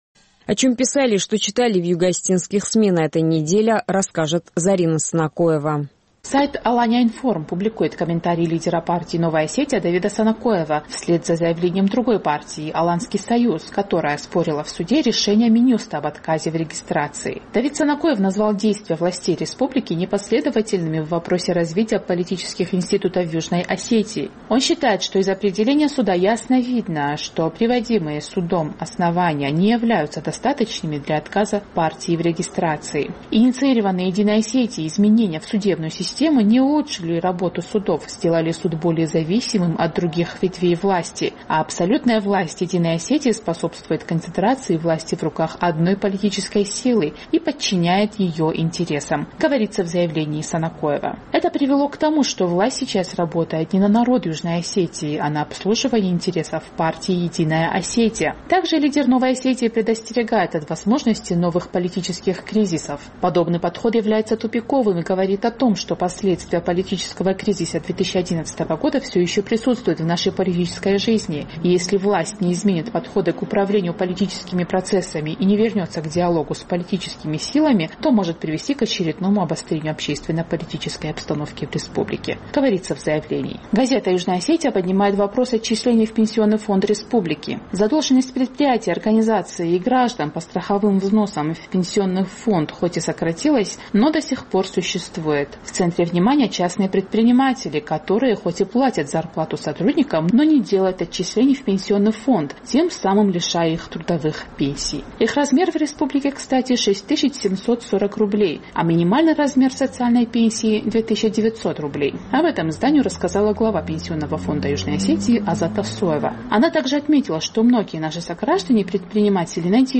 Обзор югоосетинской прессы